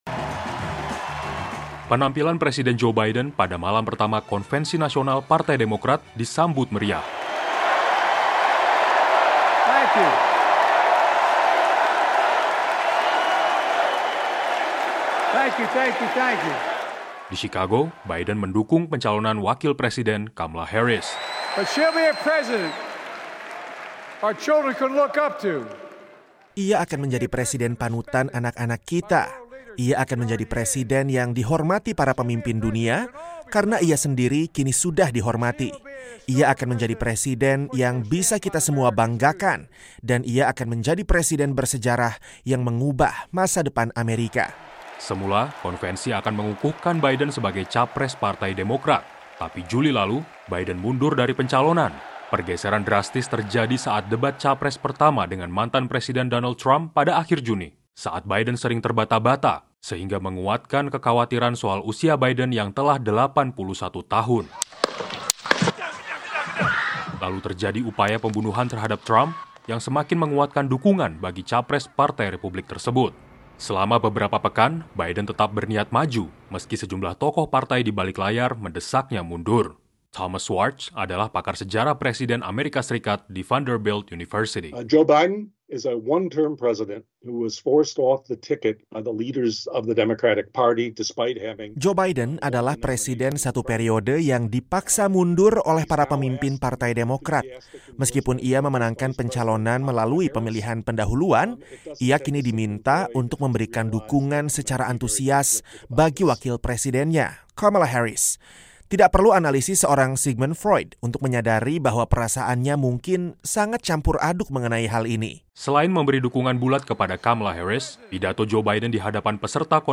Penampilan Presiden AS Joe Biden pada malam pertama Konvensi Nasional Partai Demokrat disambut meriah. “Terima kasih, Joe! Terima kasih, Joe! Terima kasih, Joe!” gemuruh peserta terdengar di United Center, arena penyelenggaraan konvensi, Senin (19/8) malam.